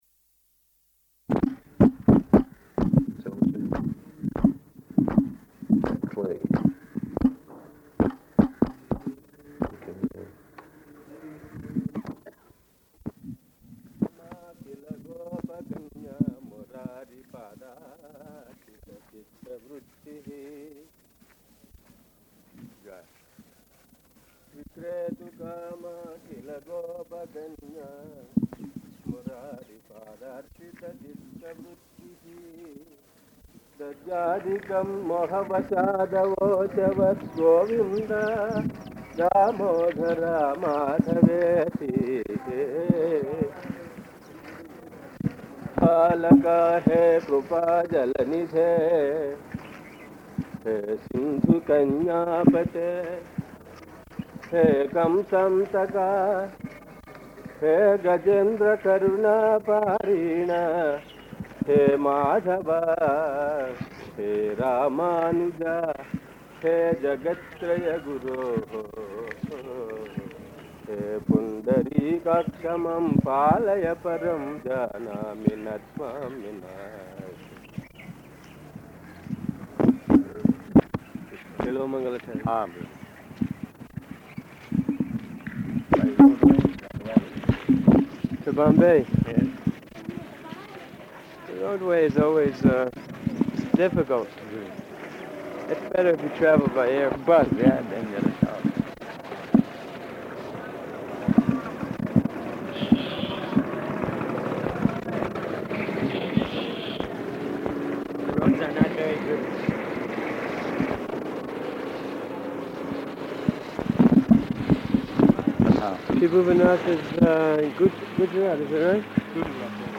Morning Walk [partially recorded]
Type: Walk
Location: Hyderabad